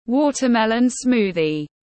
Sinh tố dưa hấu tiếng anh gọi là watermelon smoothie, phiên âm tiếng anh đọc là /ˈwɔtərˌmɛlən ˈsmuːðiz/
Watermelon smoothie /ˈwɔtərˌmɛlən ˈsmuːðiz/